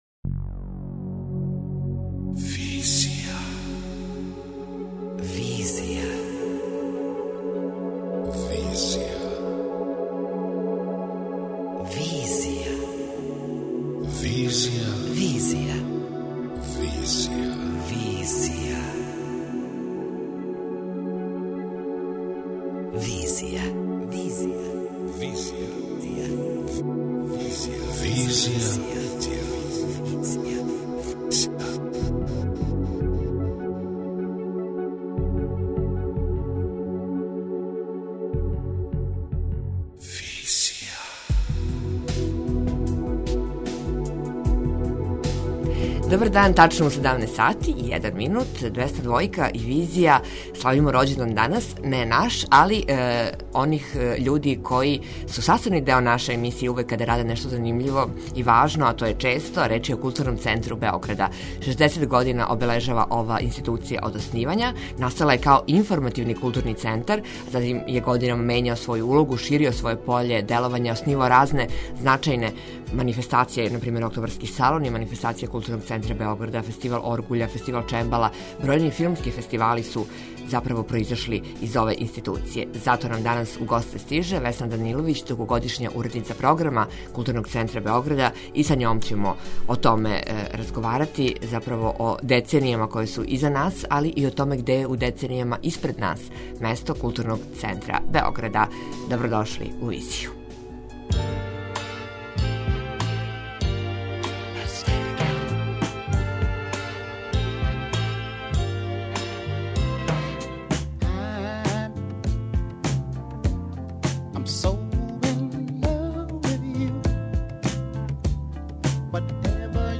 преузми : 27.80 MB Визија Autor: Београд 202 Социо-културолошки магазин, који прати савремене друштвене феномене.